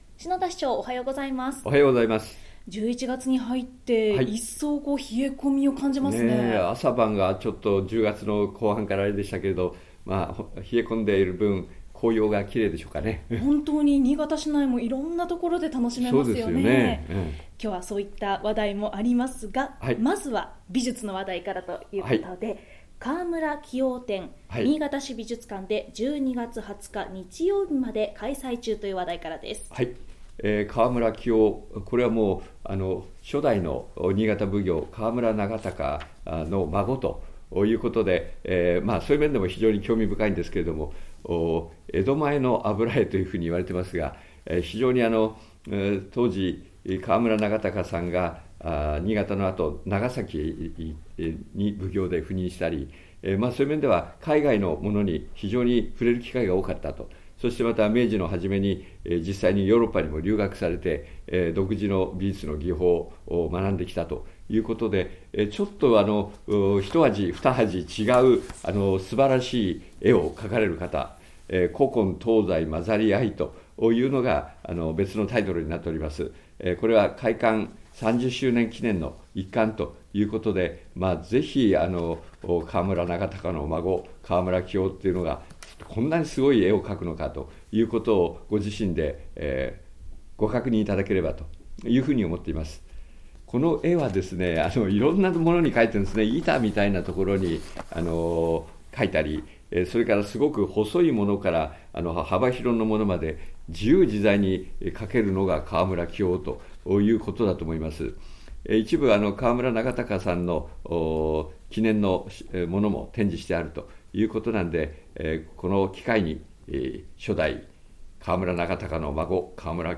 2015年11月13日（金）放送分 | 篠田市長の青空トーク